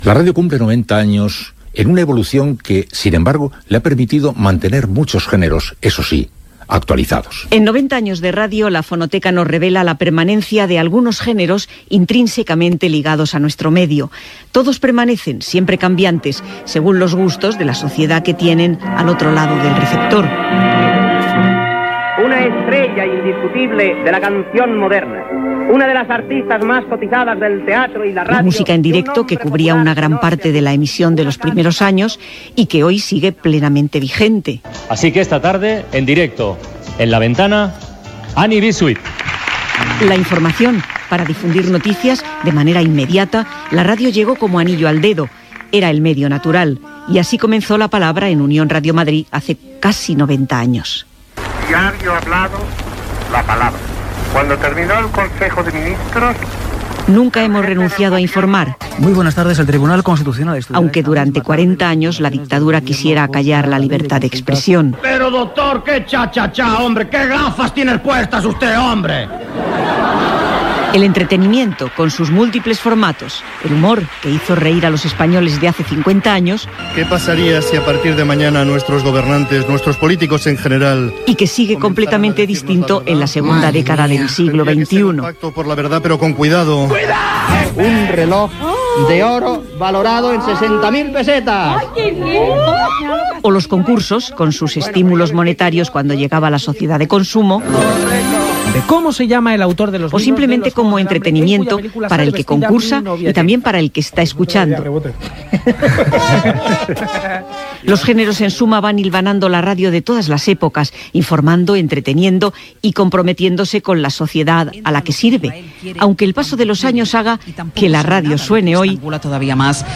Surten les veus de Bobby Deglané, Alberto Oliveras, Juana Ginzo, Matilde Conesa, Vicente Marco, Carlos Llamas, Joaquín Luqui...
Divulgació